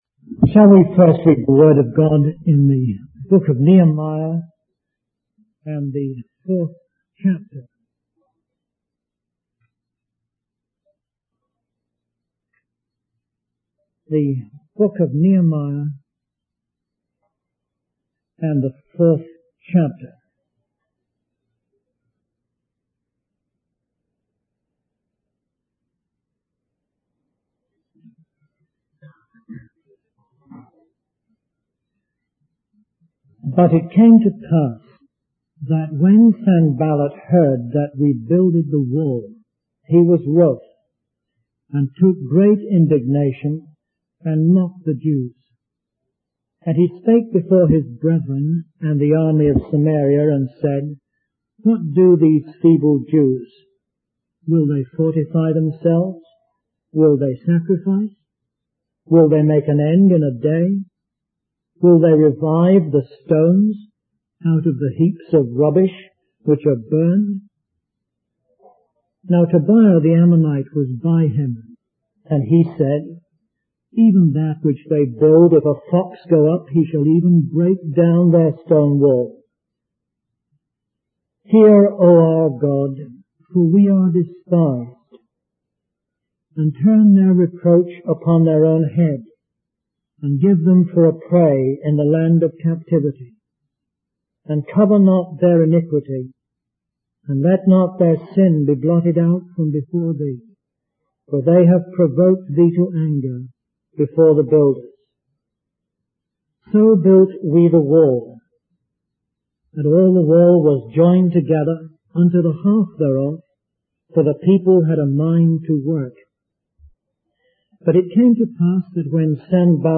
In this sermon, the speaker begins by using an illustration from Jonathan Edwards about the convergence of God's providence. He emphasizes the importance of unity and prayer in times of confusion.